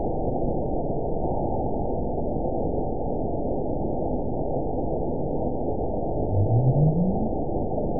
event 920123 date 02/24/24 time 02:32:40 GMT (1 year, 3 months ago) score 9.70 location TSS-AB01 detected by nrw target species NRW annotations +NRW Spectrogram: Frequency (kHz) vs. Time (s) audio not available .wav